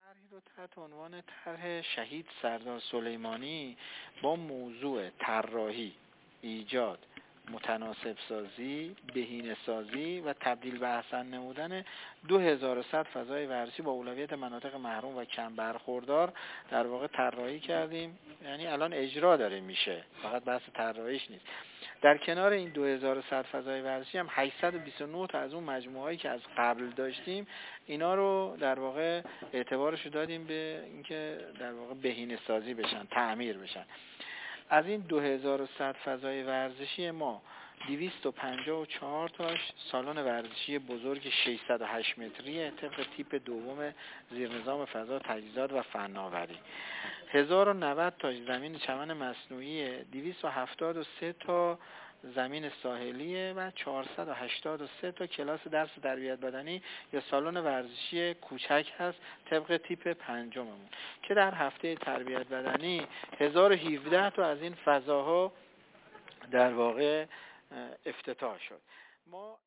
محمد جعفری، مدیرکل دفتر تربیت‌بدنی و فعالیت‌های ورزشی وزارت آموزش‌وپرورش در گفت‌وگو با ایکنا در رابطه با طرح «شهید سردار سلیمانی» گفت: ما در آموزش‌و‌پرورش اعتقاد داریم که فقط محیط‌های آموزشی مکان‌ یادگیری نیستند بلکه باید محیط‌های یادگیری در فرایند تعلیم و تربیت رسمی و عمومی کشور ایجاد شود.